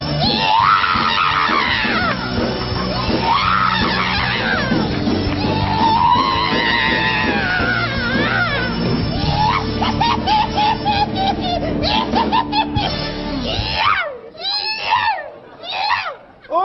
iaaaa Meme Sound Effect